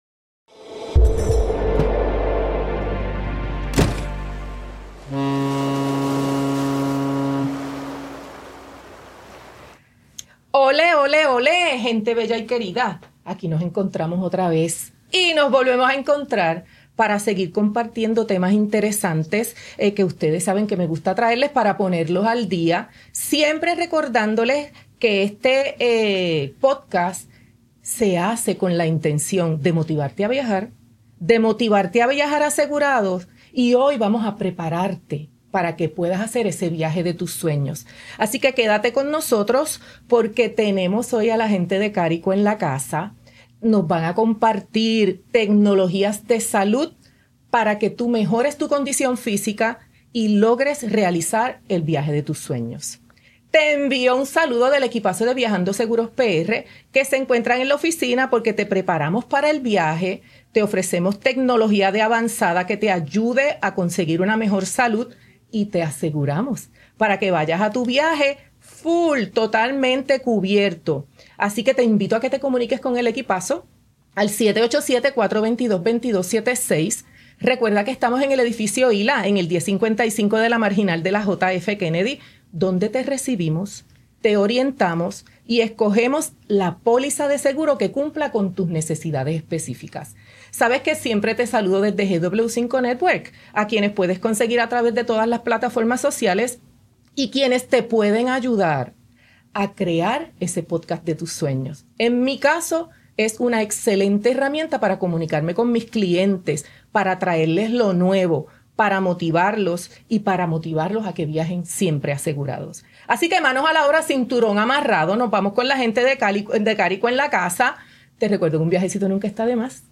En esta entrevista exclusiva, conversamos con un representante de Carico, una empresa internacional con más de 50 años ofreciendo productos premium para la salud y el bienestar de las familias. Desde utensilios de cocina de acero quirúrgico, hasta sistemas de purificación de agua y aire, Carico transforma la forma en que vivimos y viajamos.